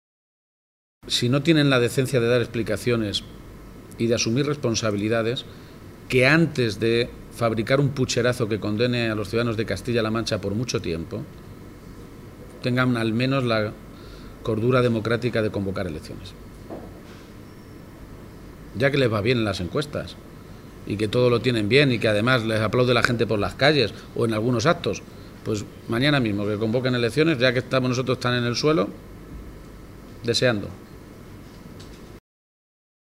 García-Page, que compartió un desayuno informativo con los medios de comunicación en Cuenca, insistió una vez más en la necesidad de que la presidenta de Castilla-La Mancha, María Dolores de Cospedal, comparezca en las Cortes regionales tras las últimas informaciones desprendidas del denominado caso Bárcenas.